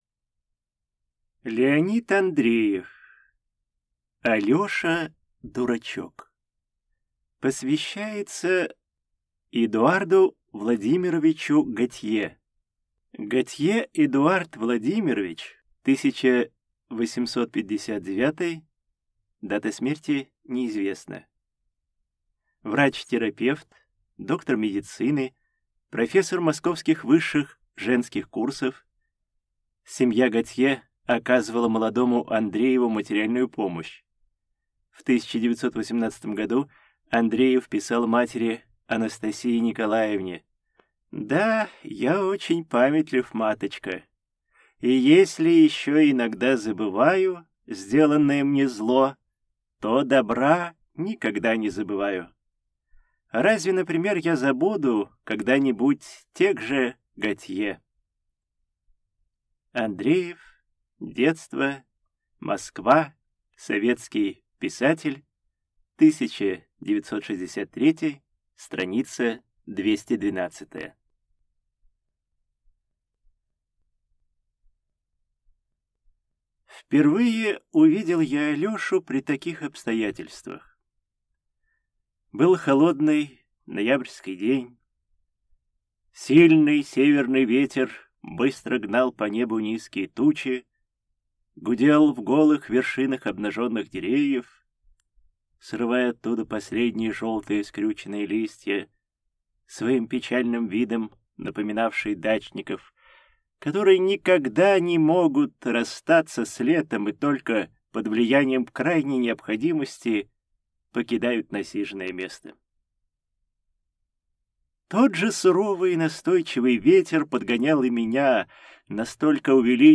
Аудиокнига Алёша-дурачок